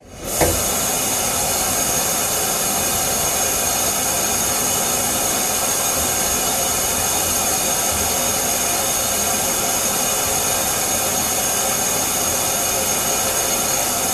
Fountains
Water Fountain At Skywalker South Smooth, On and Loop